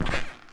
poof_sound.wav